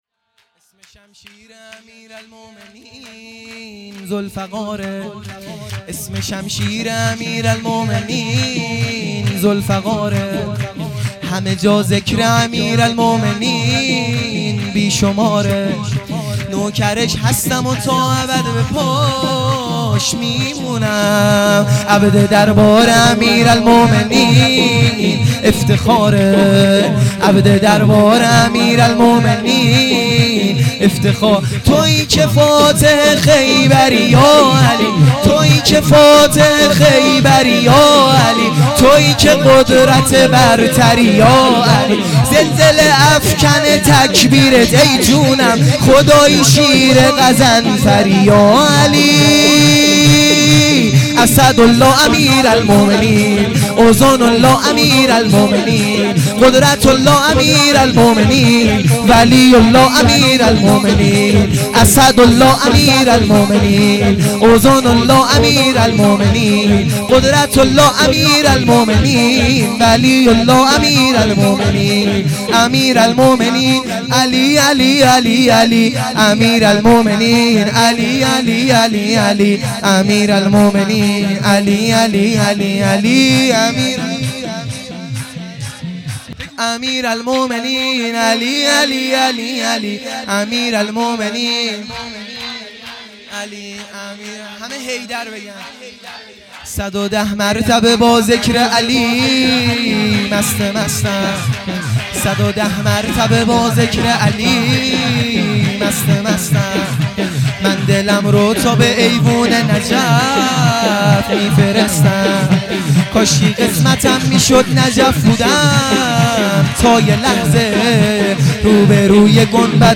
سرود | اسم شمشیر امیرالمومنین